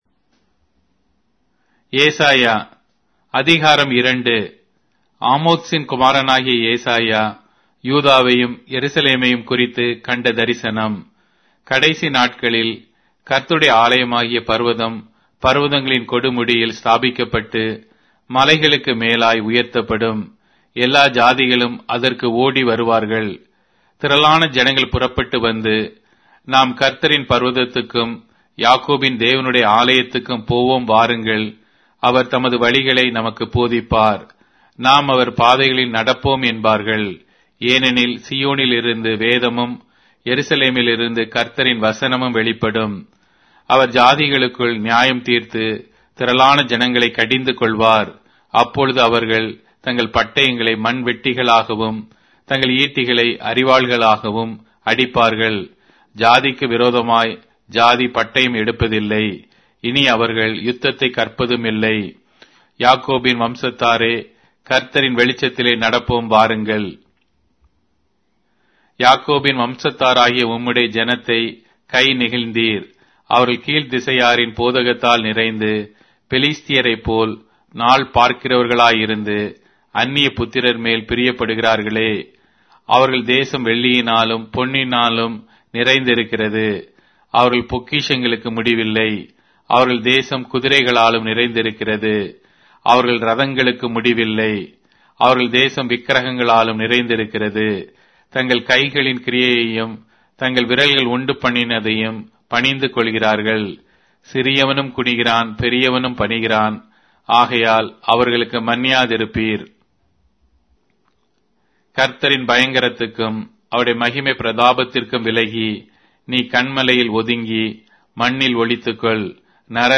Tamil Audio Bible - Isaiah 18 in Ervbn bible version